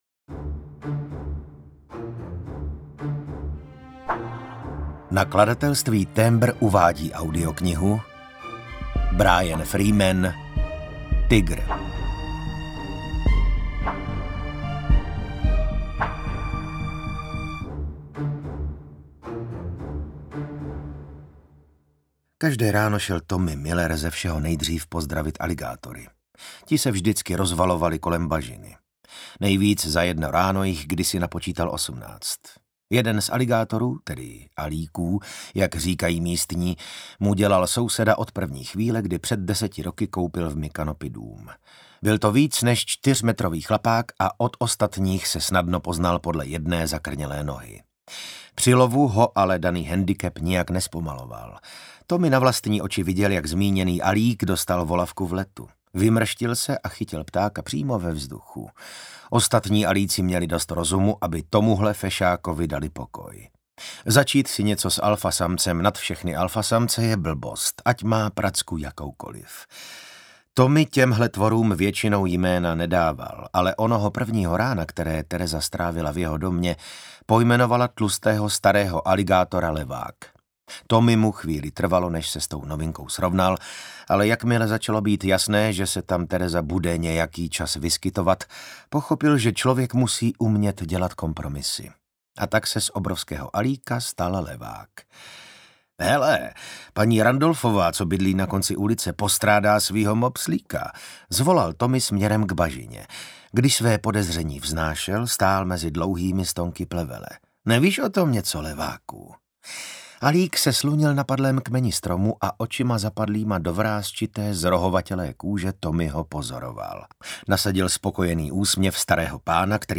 Tygr audiokniha
Ukázka z knihy